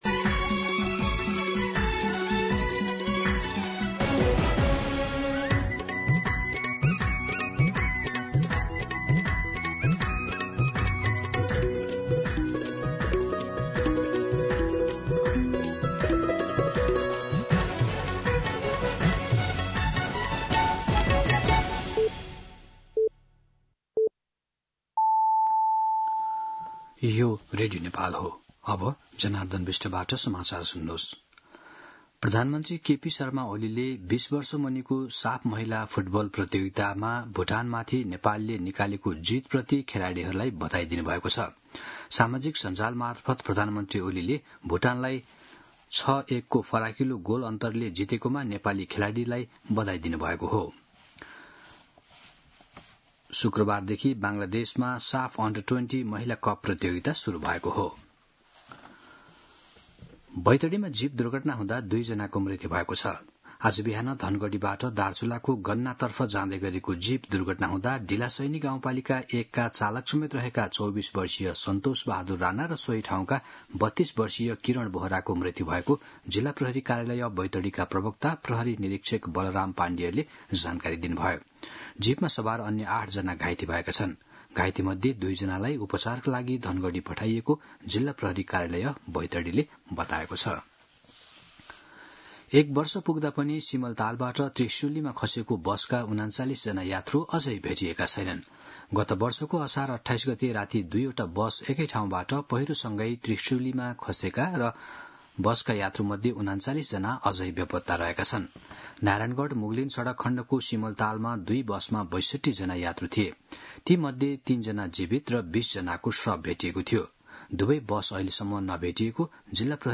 दिउँसो १ बजेको नेपाली समाचार : २८ असार , २०८२
1-pm-Nepali-News.mp3